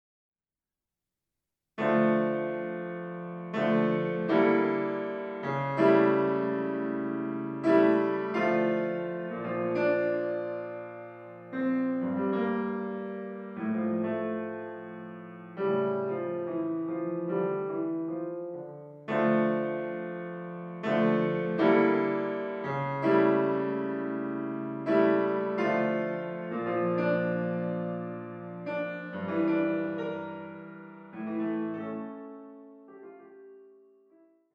for the Left Hand